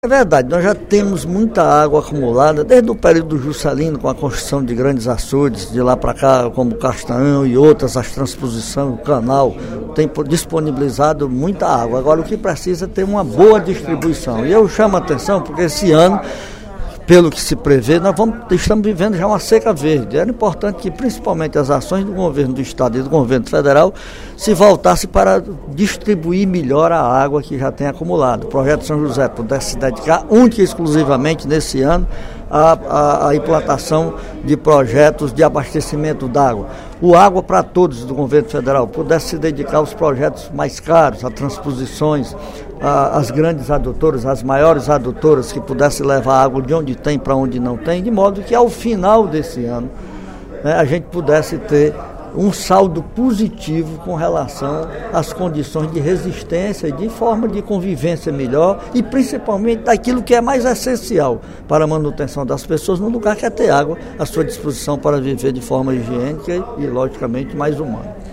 Na sessão legislativa desta quinta-feira (22/03), o parlamentar ressaltou a necessidade de ações do poder público para minimizar os prejuízos na agricultura e pecuária do Interior cearense.